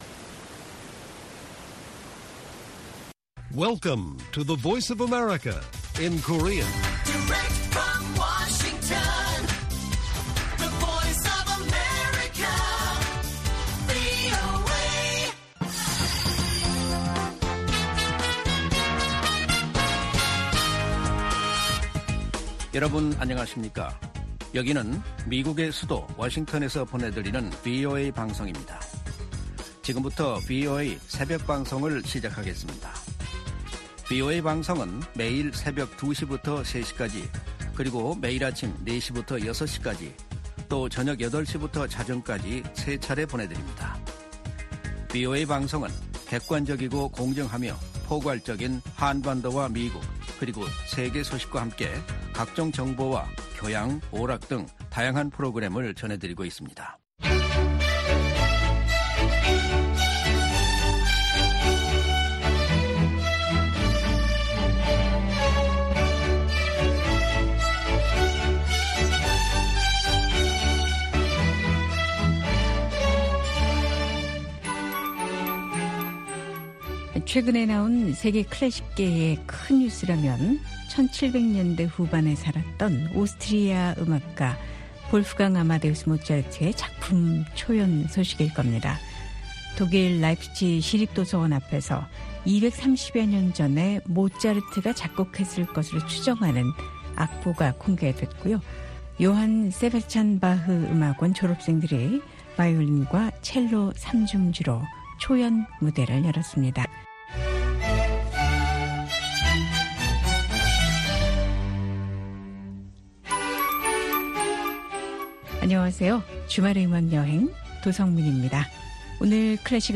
VOA 한국어 방송의 일요일 새벽 방송입니다. 한반도 시간 오전 2:00 부터 3:00 까지 방송됩니다.